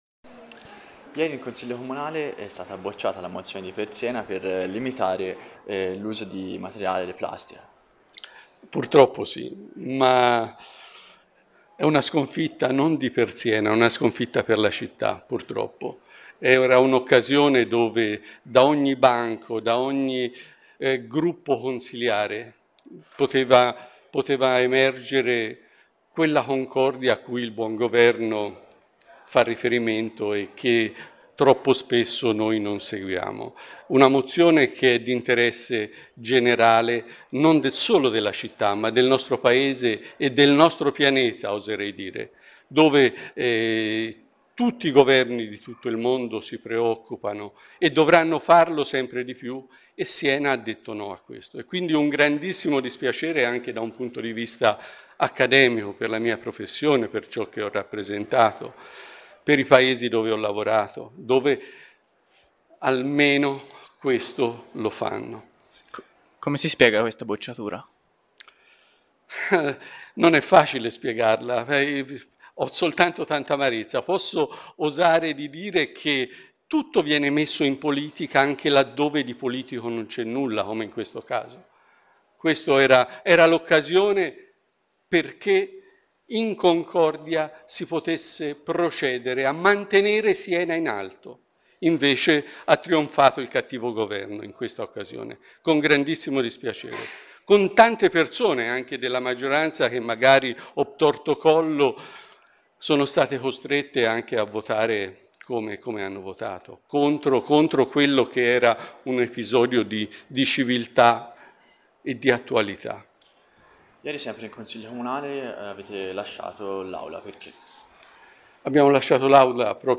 Ai nostri microfoni Massimo Mazzini di Per Siena sulla bocciatura della mozione e la conseguente uscita dal consiglio.